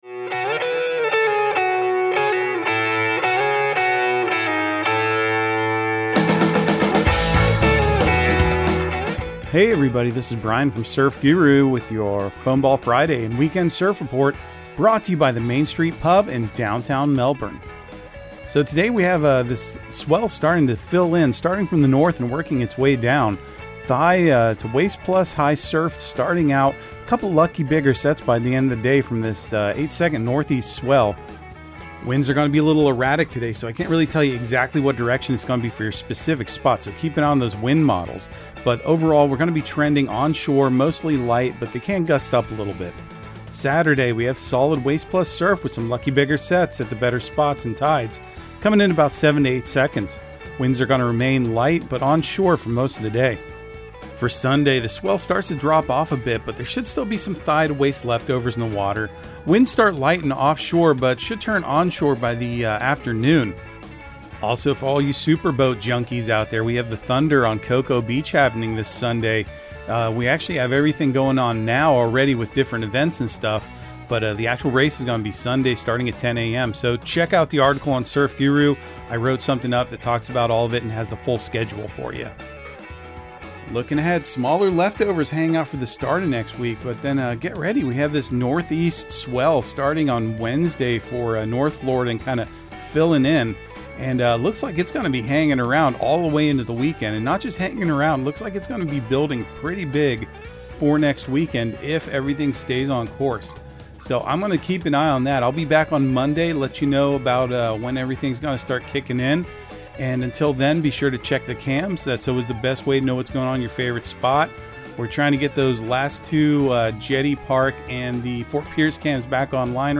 Surf Guru Surf Report and Forecast 05/19/2023 Audio surf report and surf forecast on May 19 for Central Florida and the Southeast.